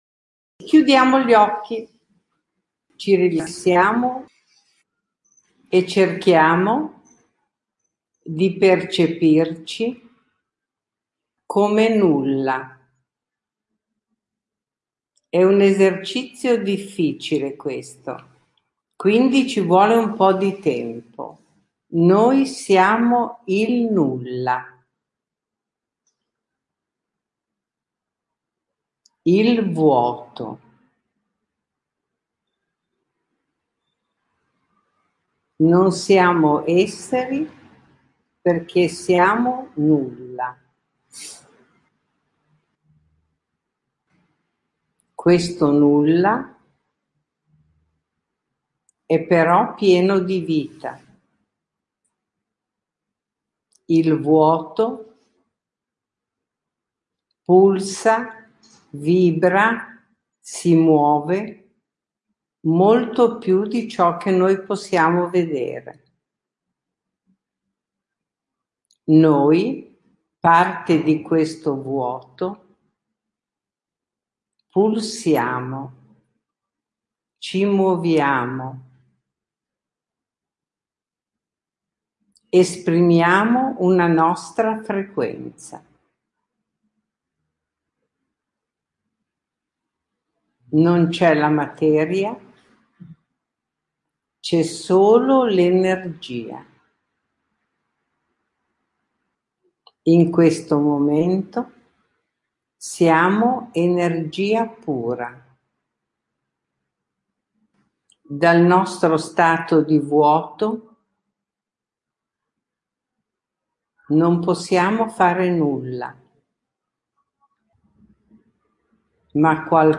Meditazioni
Il-Nulla-meditazione-.mp3